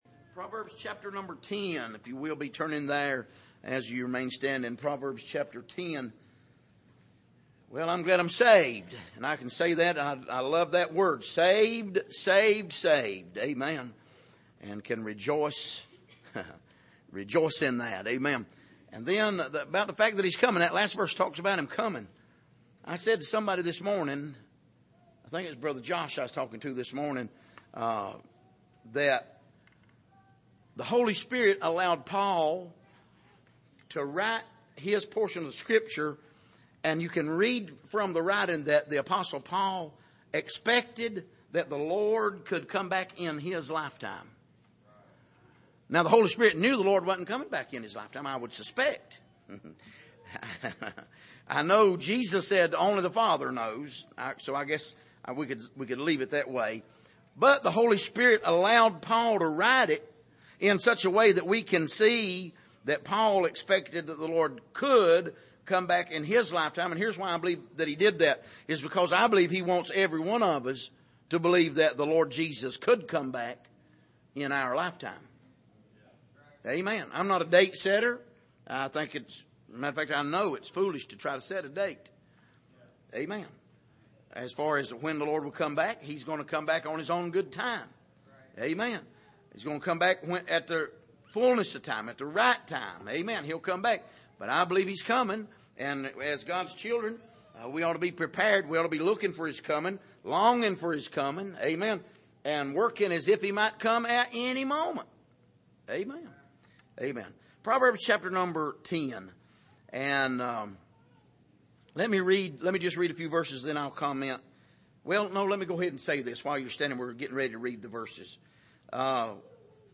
Passage: Proverbs 10:1-5 Service: Sunday Evening